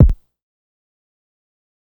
Torture Rack Kick2.wav